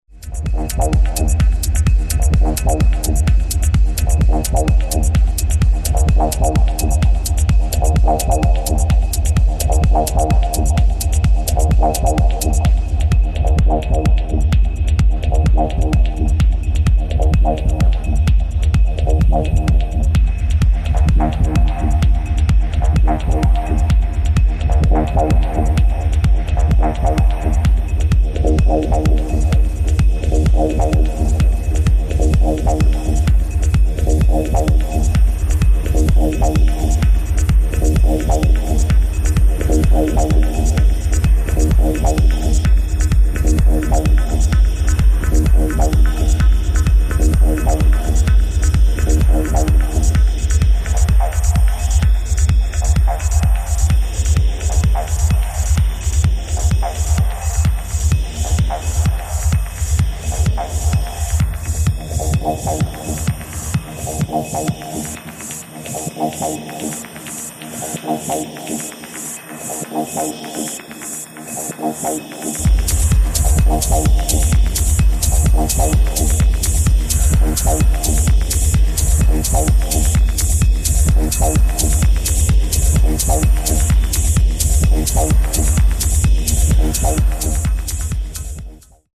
[TECHNO]